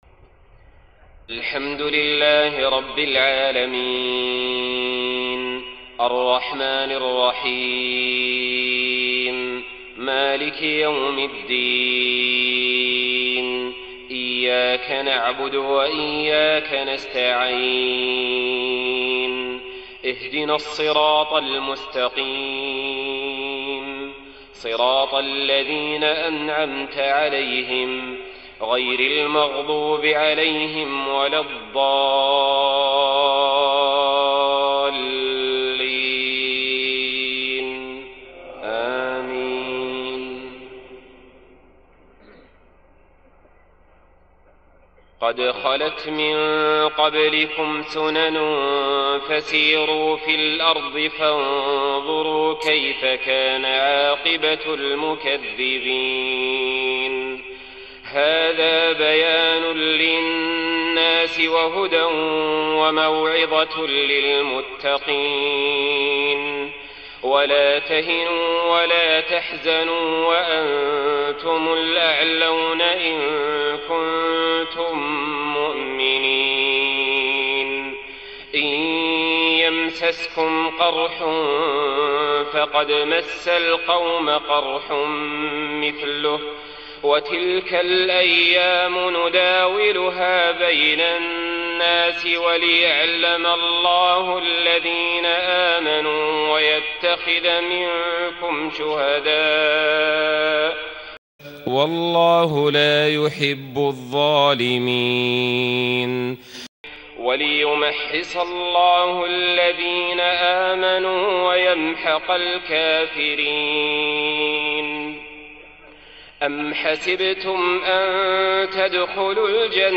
صلاة العشاء 30 ذو الحجة 1429هـ من سورة آل عمران 137-148 > 1429 🕋 > الفروض - تلاوات الحرمين